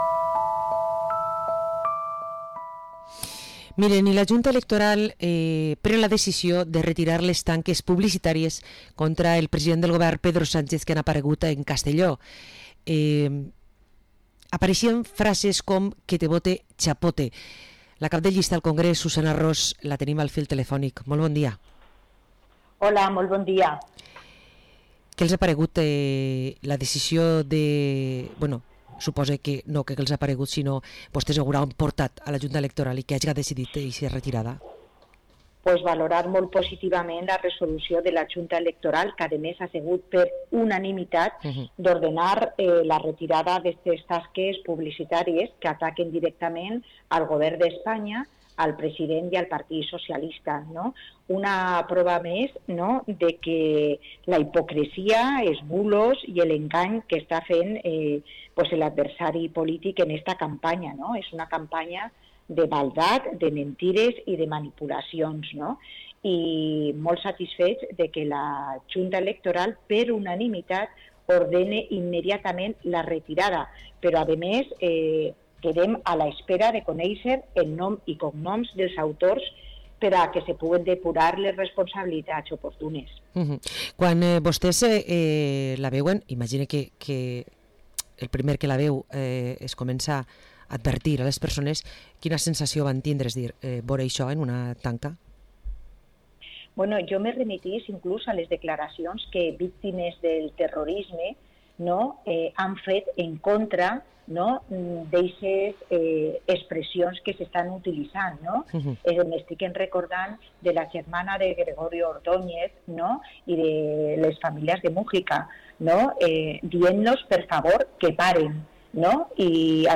Entrevista a la diputada nacional del PSOE, Susana Ros